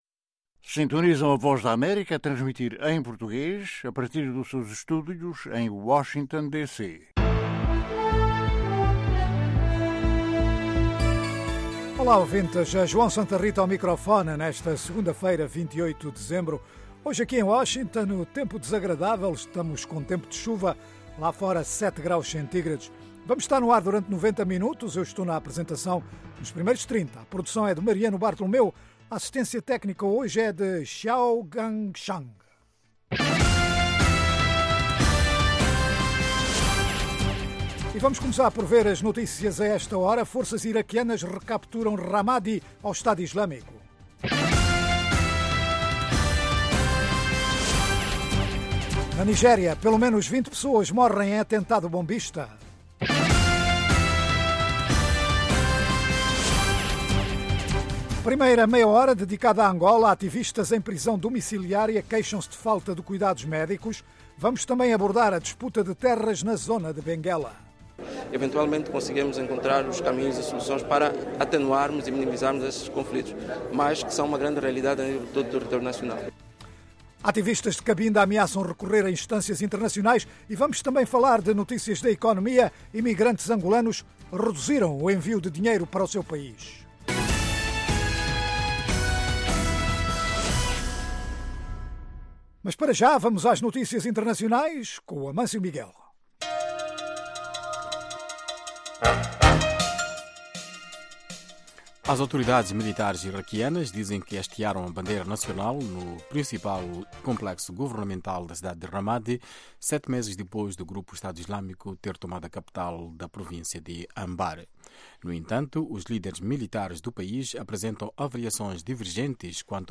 Um programa orientado aos países Lusófonos de África, sem esquecer o Brasil e comunidades de língua portuguesa noutras partes do mundo. Oferece noticias, informação e analises e divide-se em três meias-horas: 1) Orientado a Angola - com histórias enviadas de Angola, por jornalistas em todo o país sobre os mais variados temas. 2) Notícias em destaque na África lusófona e no mundo, 3) Inclui as noticias mais destacadas do dia, análises, artes e entretenimento, saúde, questões em debate em África.